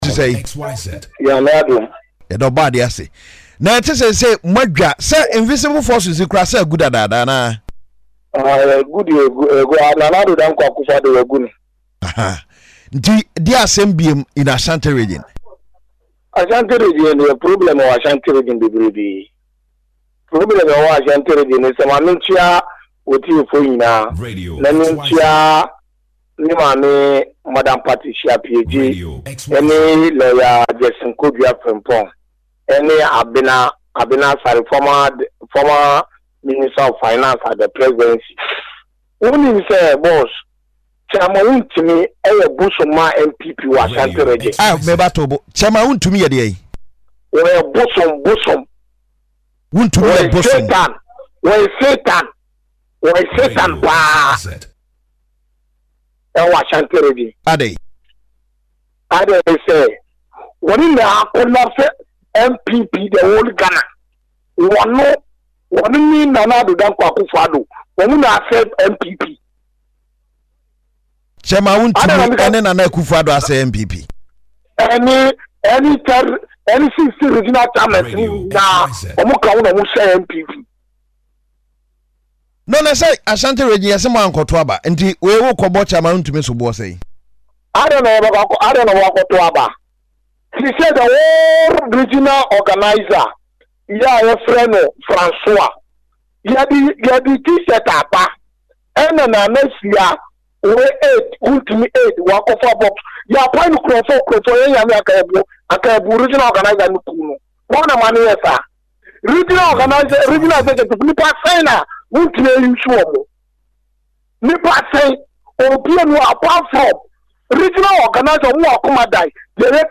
alleged in an interview that Wontumi’s leadership contributed significantly to the party’s downfall in its traditional stronghold.